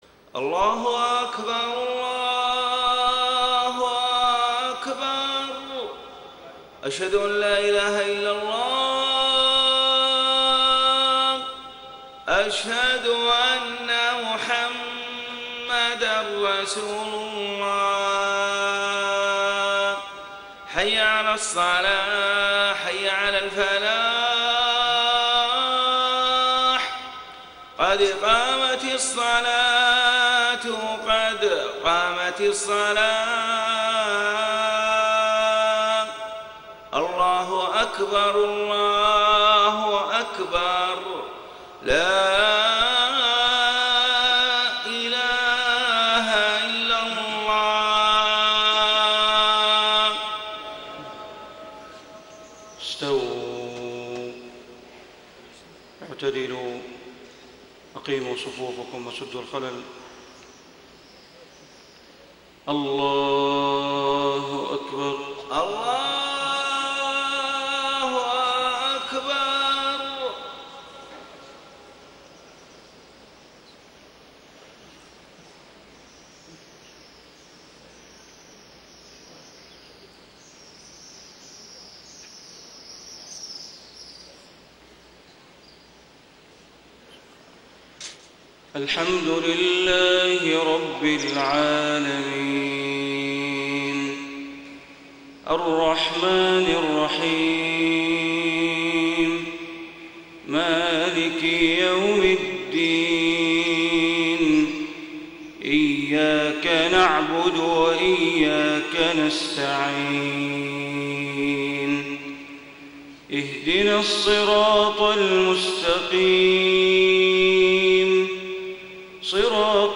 صلاة الفجر 3 - 1 - 1435هـ من سورة الرعد > 1435 🕋 > الفروض - تلاوات الحرمين